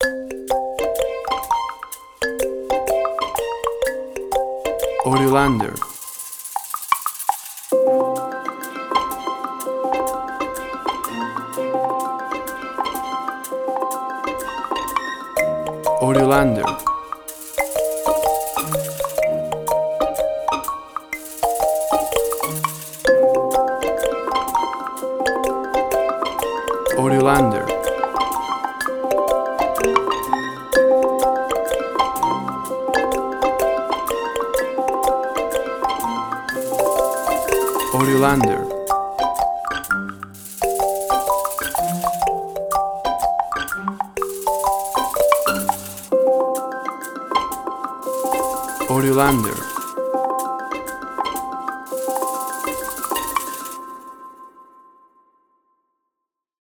Light and joyfull magical music
Tempo (BPM): 124